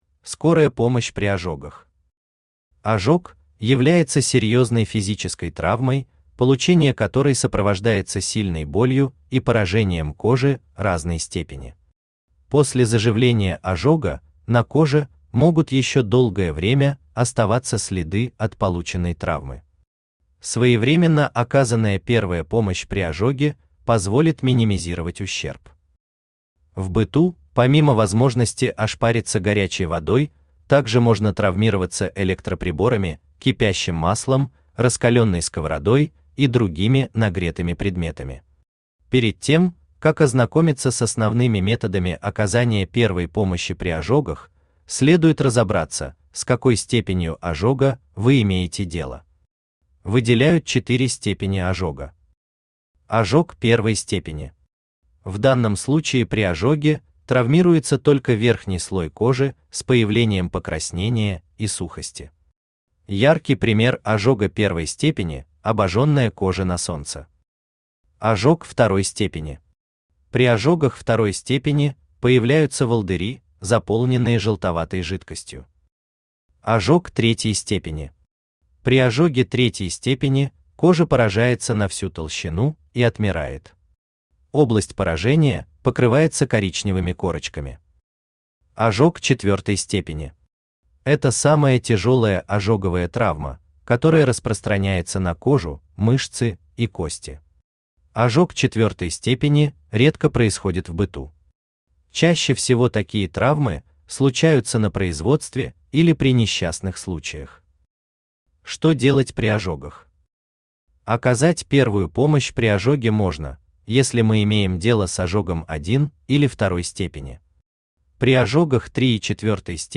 Том 6 Автор Геннадий Анатольевич Бурлаков Читает аудиокнигу Авточтец ЛитРес.